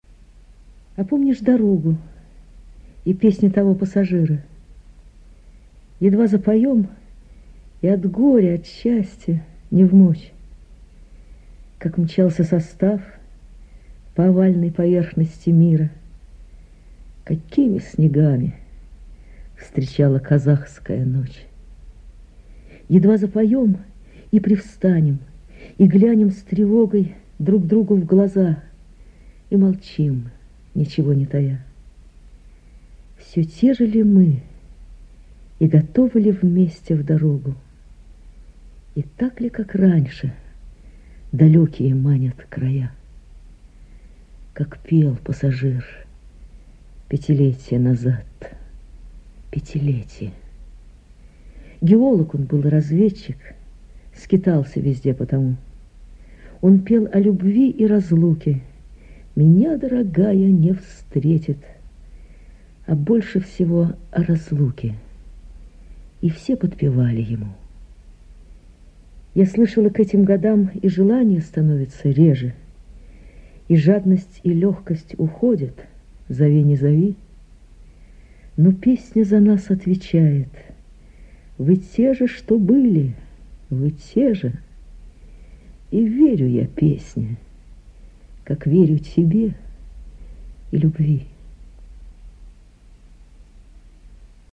2. «Ольга Берггольц лирика. Читает Татьяна Доронина – А помнишь дорогу и песню того пассажира? читает Татьяна Доронина» /